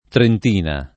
trentina [ trent & na ] s. f.